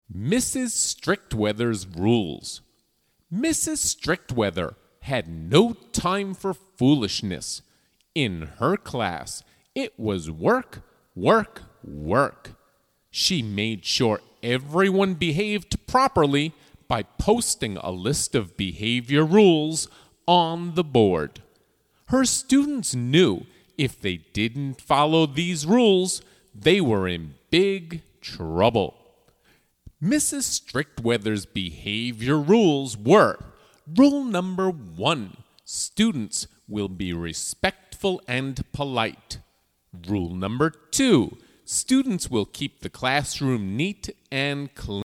Downloadable Spoken Story